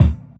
Kicks
Medicated Kick 30.wav